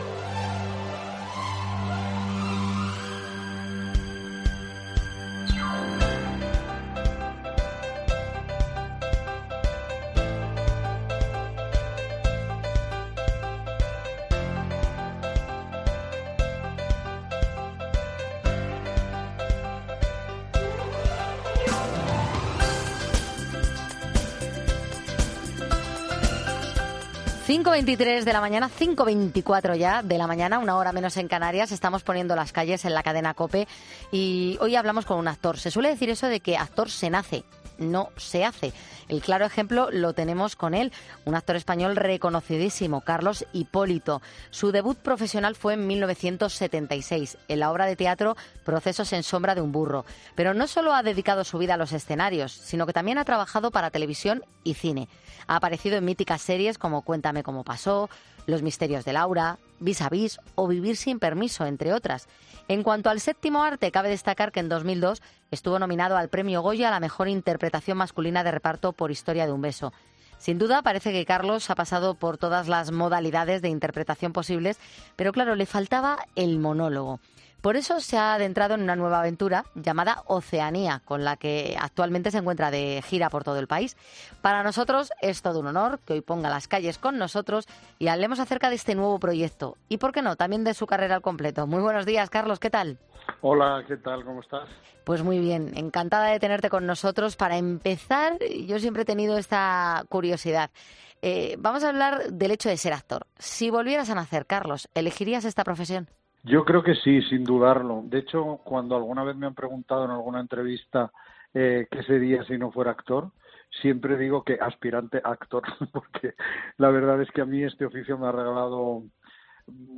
El actor, que ha aparecido en míticas series como Cuéntame como pasó o Vis a Vis, ha pasado por 'Poniendo las Calles' para contarnos con detalle su...